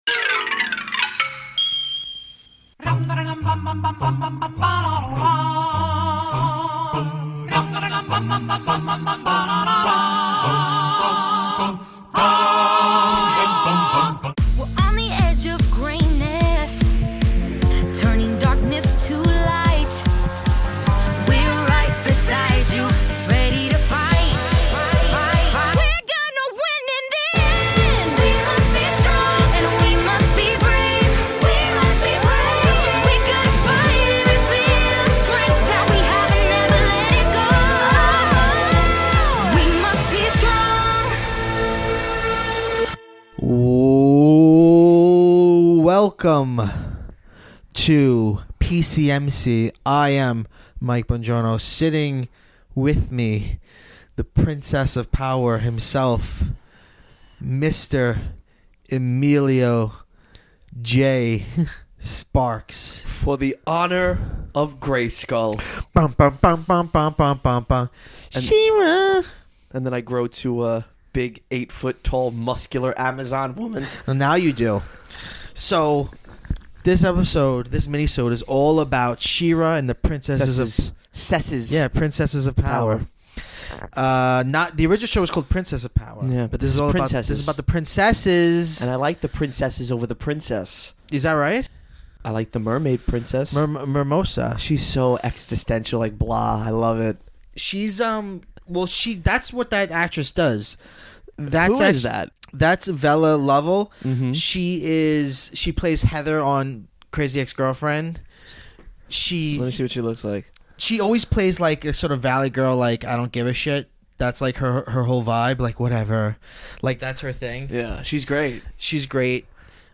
Just a couple of 30-something dudes sitting around talking Princesses - deal with it. The guys discuss their favorite characters, their favorite episodes, and of course the shows strong LGBTQIA tone.